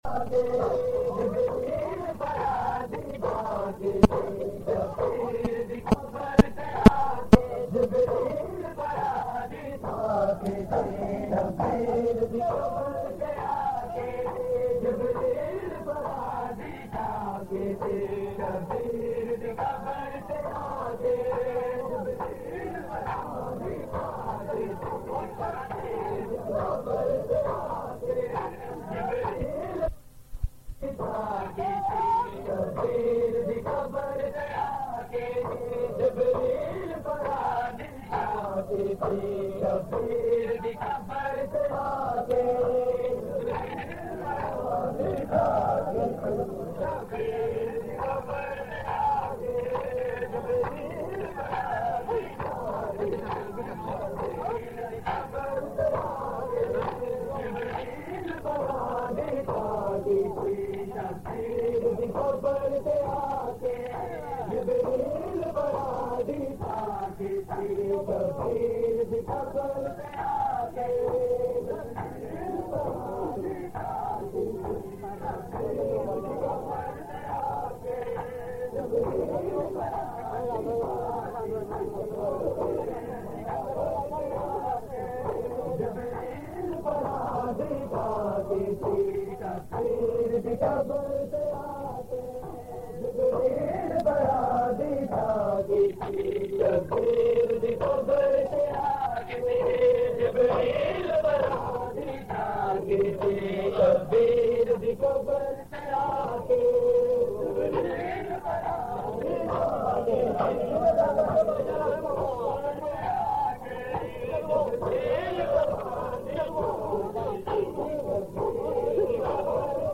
Recording Type: Live
Location: Lahore